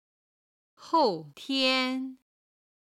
今回は今日の中国語「今天(jīn tiān)」基準に昨日、一昨日(おととい)、一昨昨日(さきおととい)、明日、明後日(あさって)、明々後日(しあさって)の中国語表現をピンインとカタカナ読みに普通語一級の資格を持つ中国人教師の発音を付けて覚える際のコツと共にご紹介します。